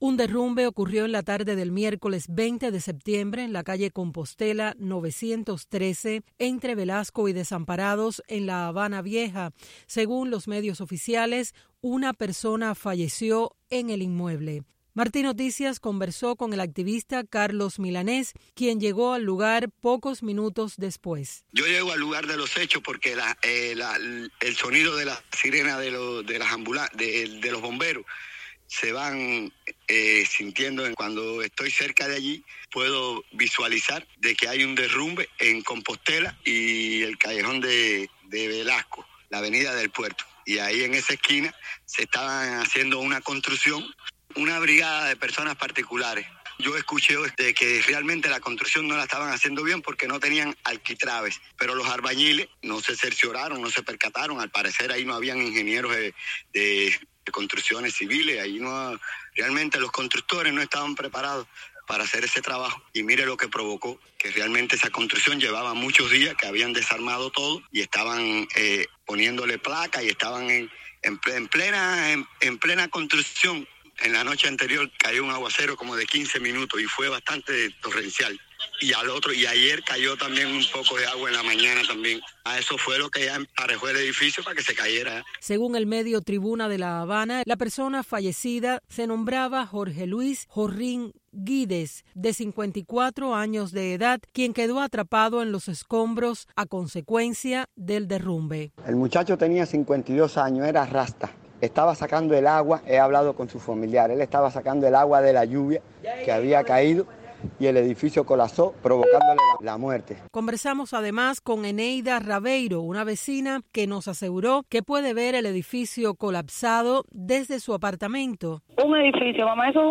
Noticias de Radio Martí
Vecinos de La Habana Vieja ofrecen detalles de cómo ocurrió el derrumbe.